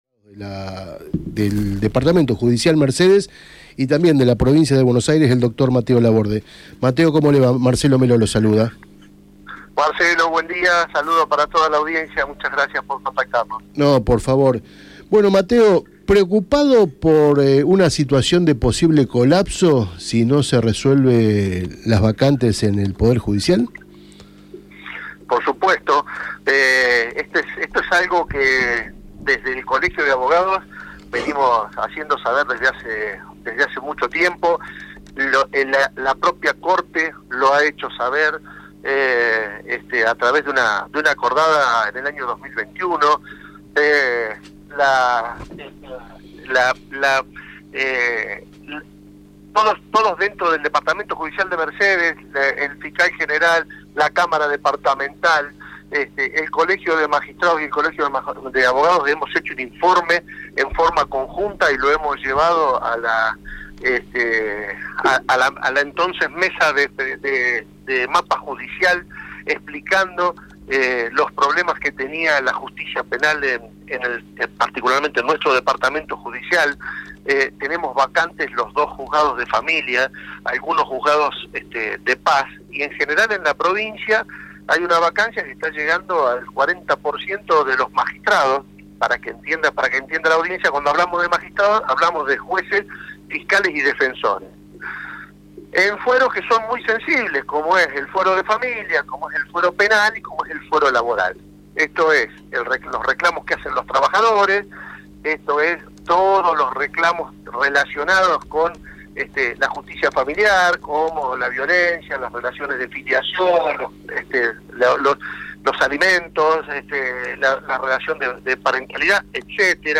en diálogo esta mañana en DatoPosta Radio